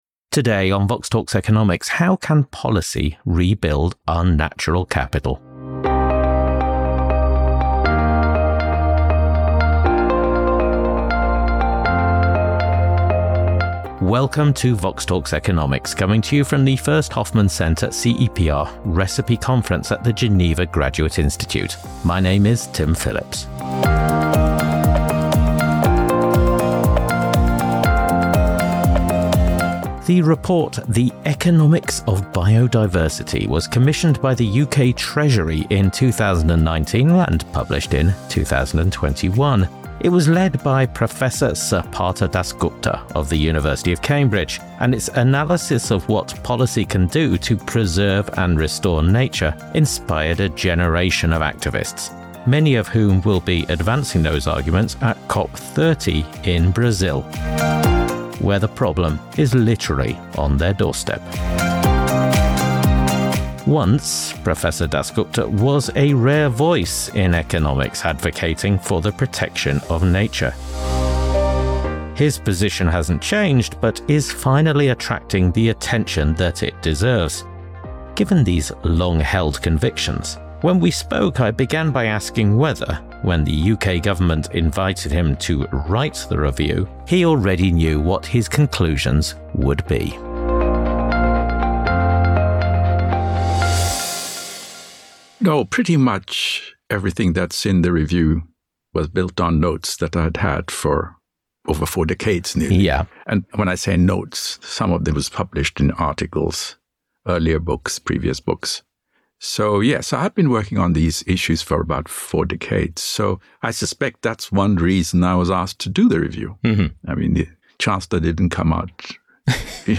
recorded at the first Hoffmann Centre / CEPR / ReCIPE Conference